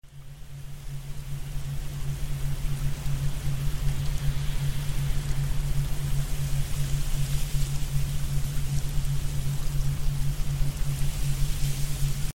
PTSD healing frequencies and subliminals sound effects free download
Sound Effect PTSD healing frequencies and subliminals with Ho’oponopono and 528hz.